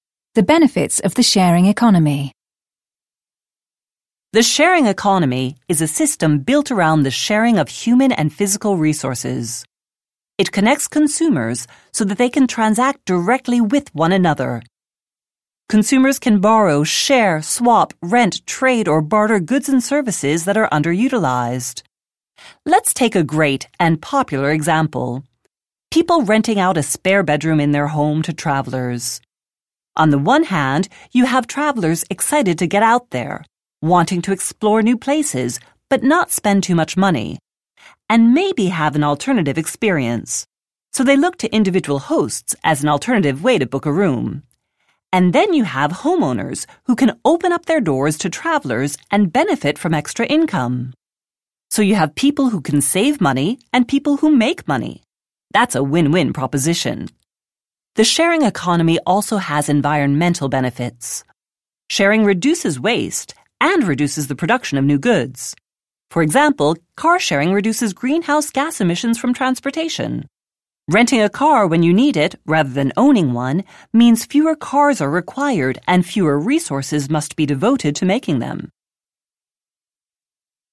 6) DICTATION : The benefits of the sharing economy